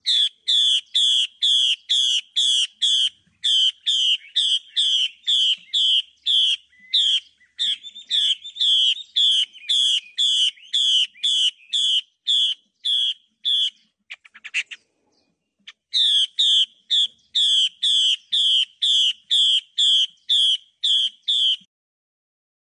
母画眉发情叫声 画眉鸟叫声母音欣赏